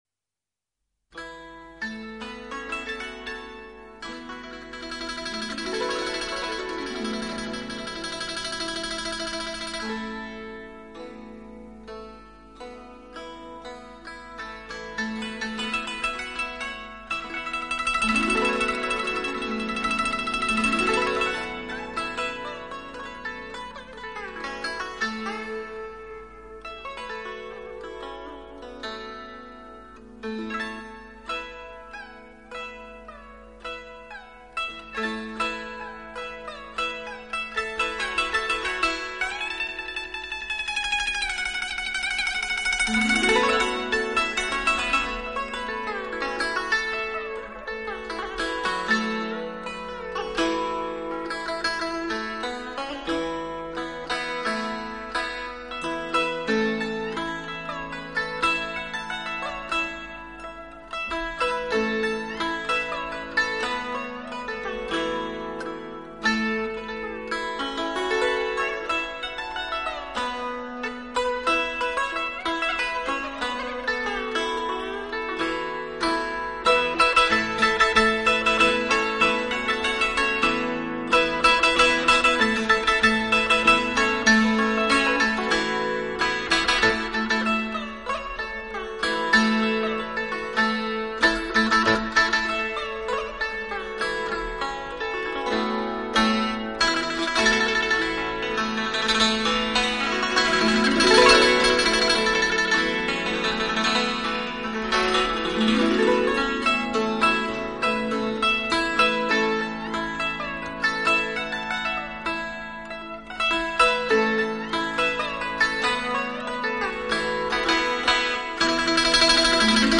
中国第一张古筝激光唱片